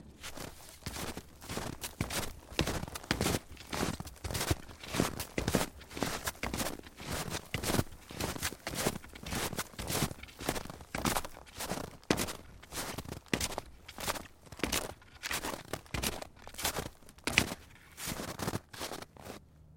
冬天" 脚步声 雪鞋 老木头2 挤满了雪 走在小道上 柔软而不粘腻2
描述：脚步雪鞋老wood2包装雪步行下来柔软而不是clacky2.flac